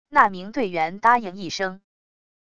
那名队员答应一声wav音频生成系统WAV Audio Player